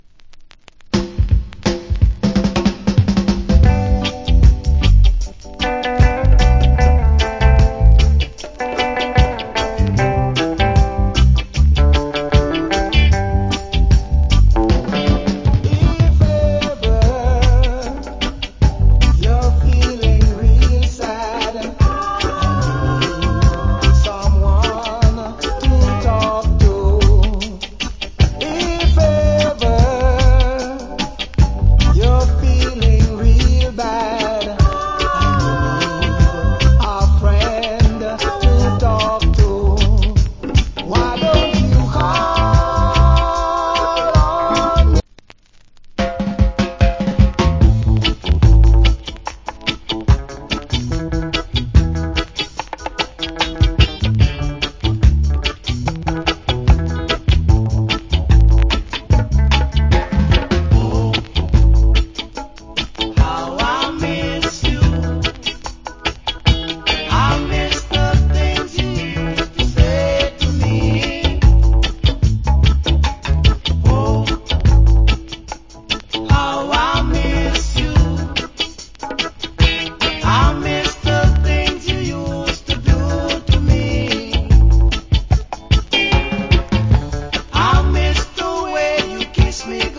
Nice UK Reggae Vocal.